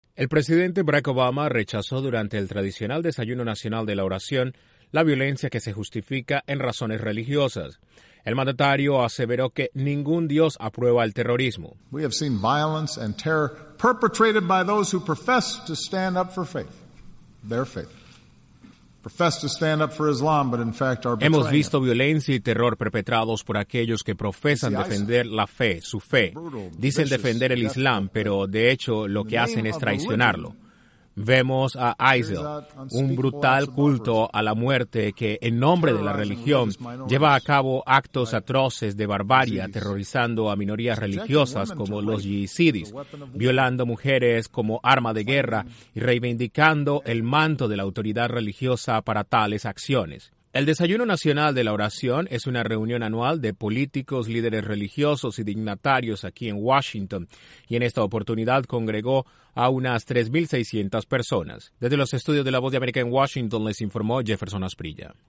En un evento junto al Dalai Lama, el presidente de Estados Unidos condenó el uso de la religión para justificar ataques inhumanos contra personas. Desde la Voz de América en Washington informa